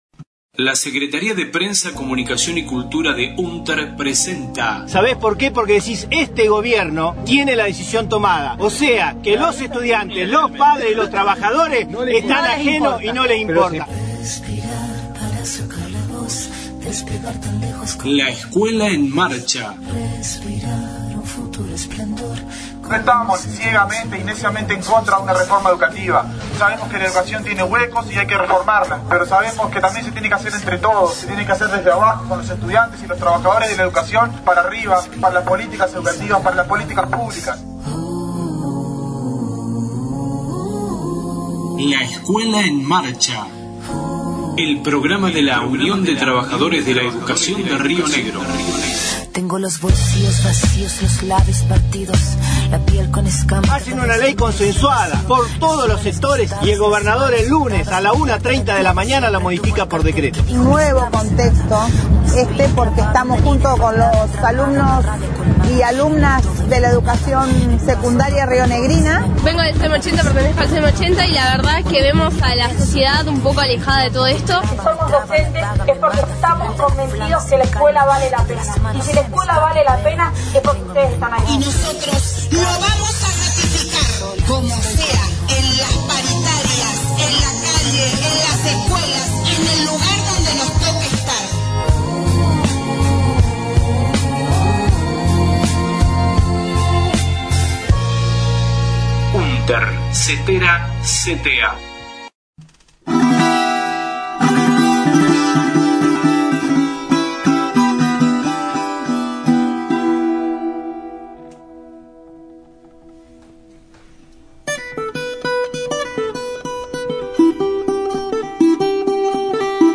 media LEEM 13/11/19 Audio en marcha en repudio al golpe de estado en Bolivia, Roca - Fiske Menuco, el 11/11/19 .